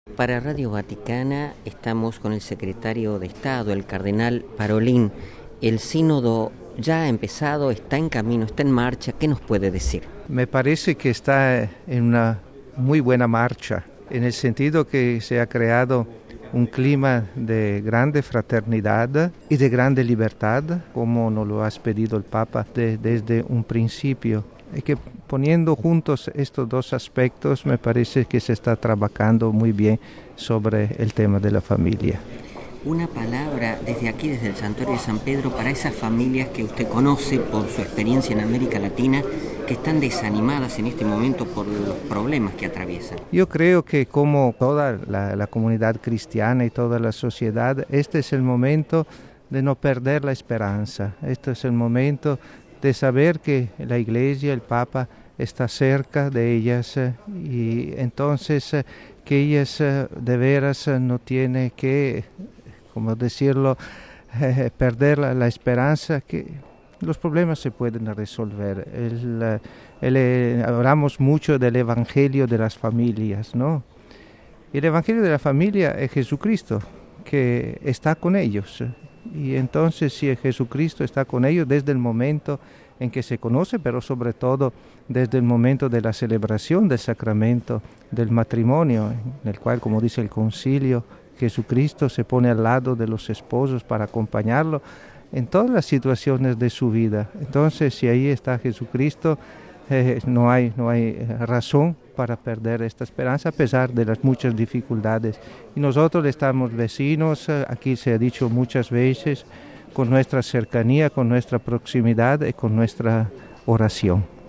Palabras del Secretario de Estado: